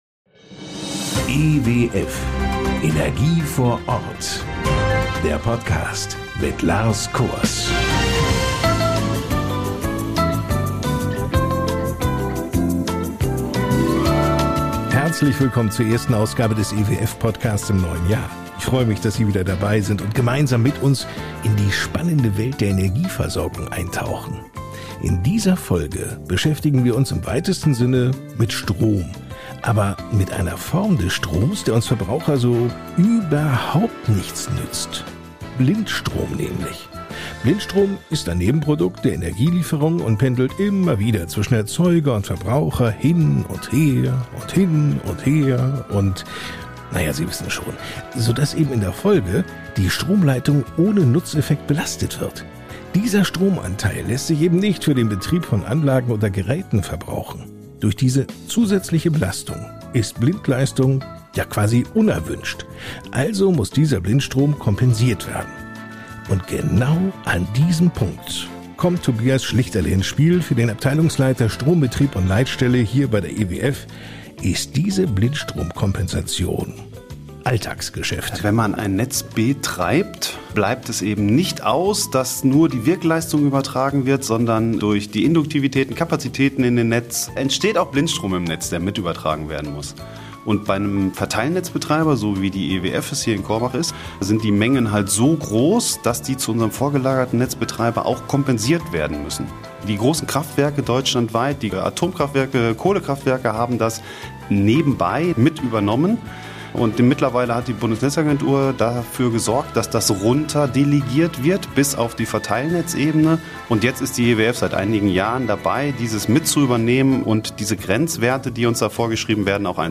Interviews mit Experten aus der Branche, die Ihnen Einblicke in